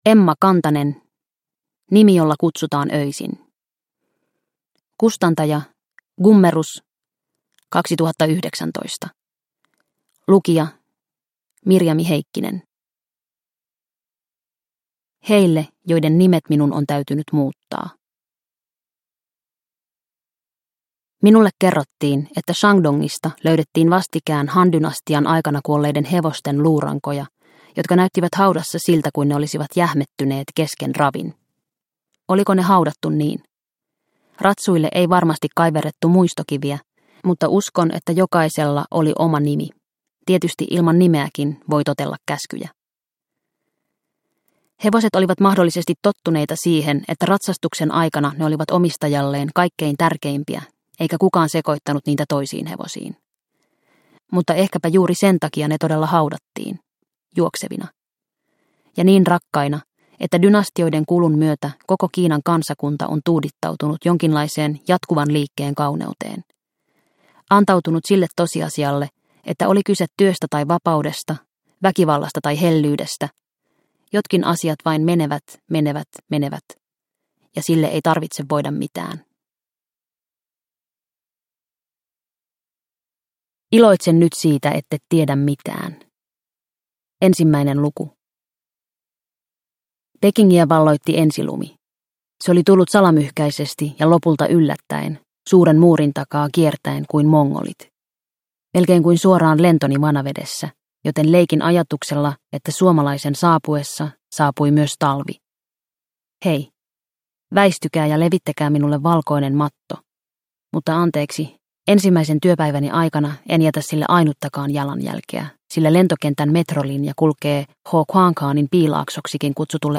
Nimi jolla kutsutaan öisin – Ljudbok – Laddas ner